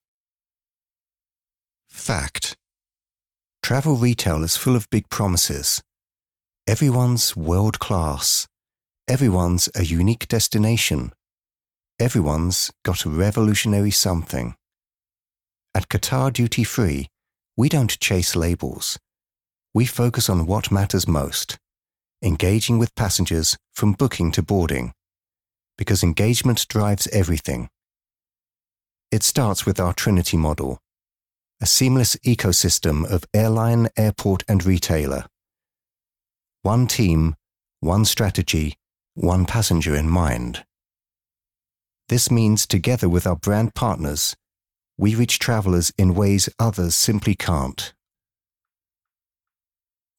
E=learning, Corporate & Industrial Voice Overs
Adult (30-50) | Older Sound (50+)